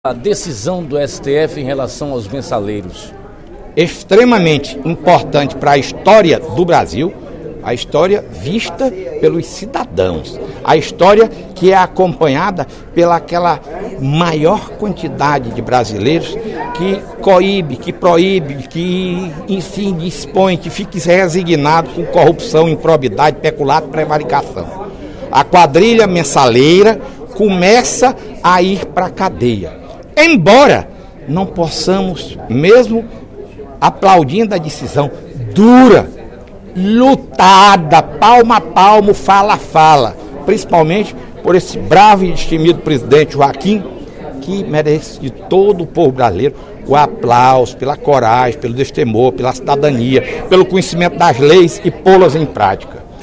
Durante o primeiro expediente da sessão desta quinta-feira (14/11), o deputado Fernando Hugo (SDD) comentou a decisão do pleno do Supremo Tribunal Federal (STF), que ontem “mandou para a cadeia os quadrilheiros do mensalão”.